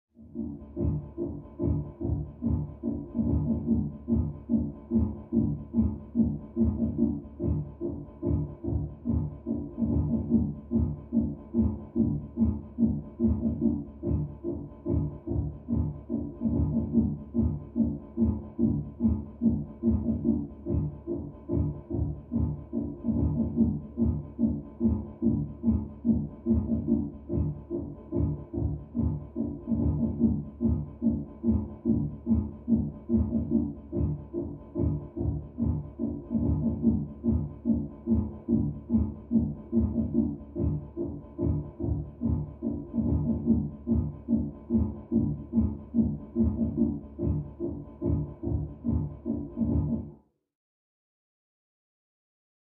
Music; Electronic Dance Beat, From Down Hallway.